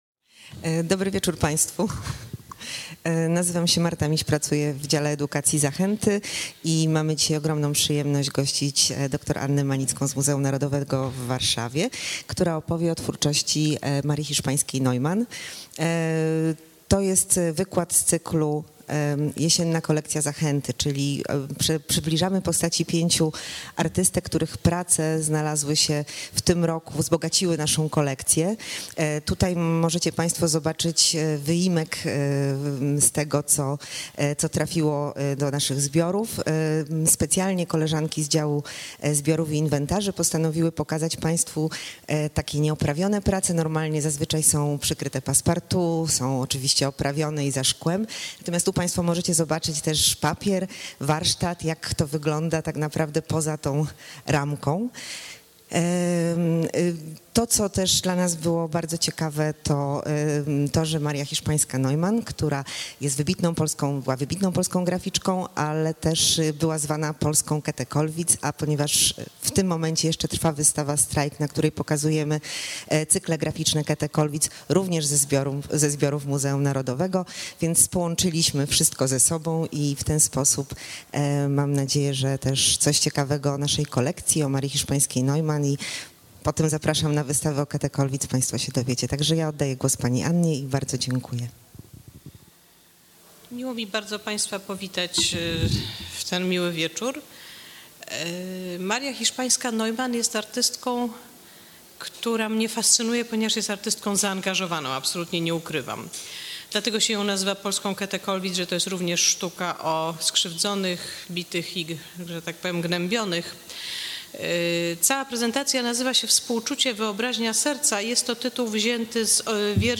wykład